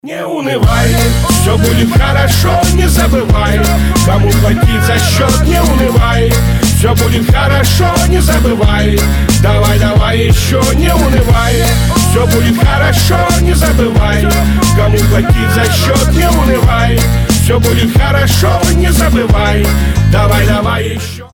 • Качество: 320, Stereo
позитивные
Хип-хоп
русский рэп
мотивирующие